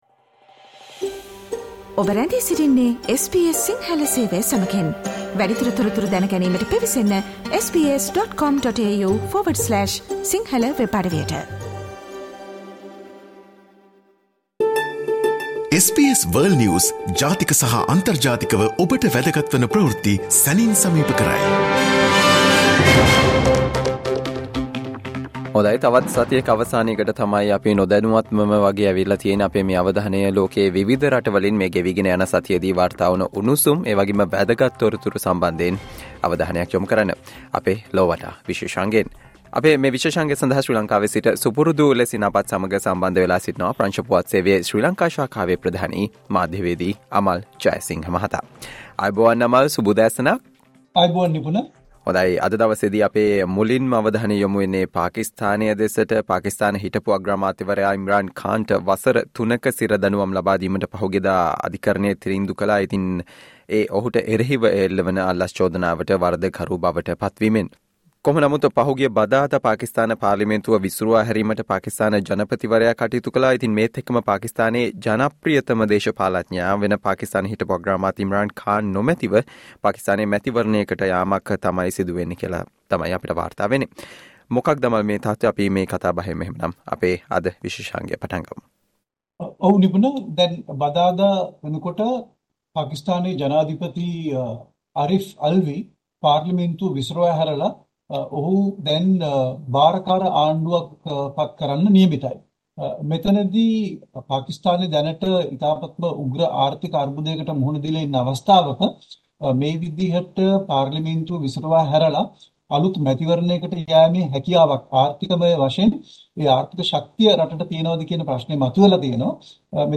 World's prominent news highlights in 12 minutes - listen to the SBS Sinhala Radio weekly world News wrap every Friday